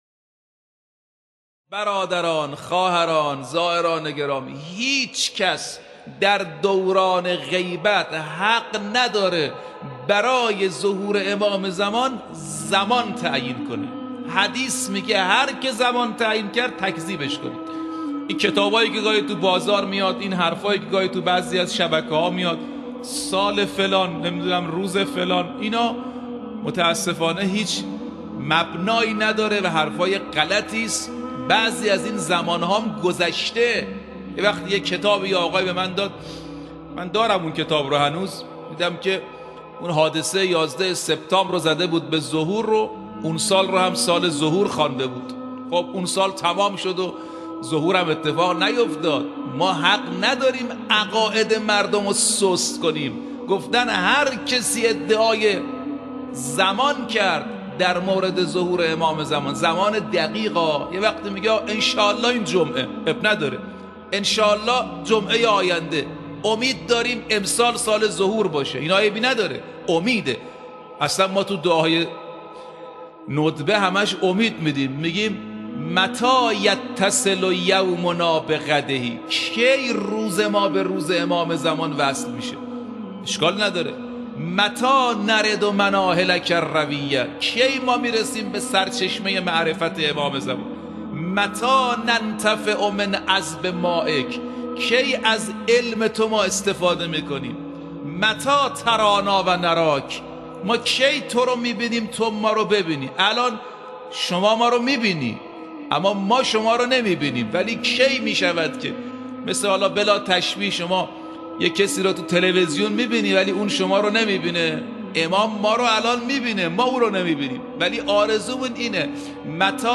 فرازی از سخنان